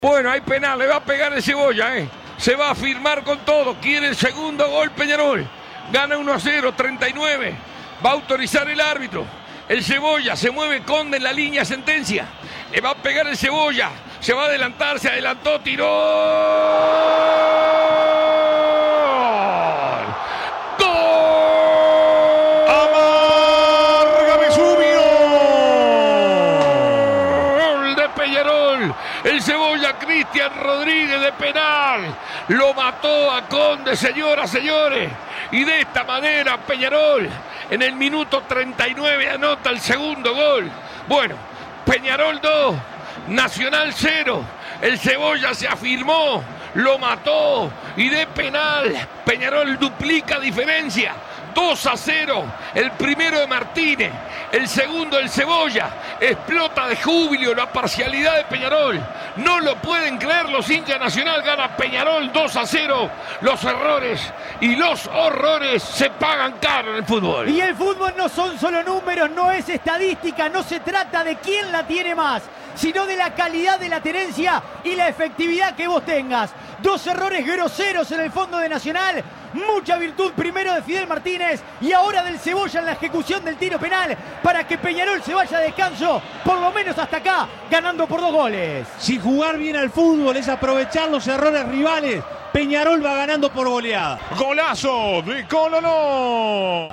Escuchá los goles relatados por Alberto Sonsol.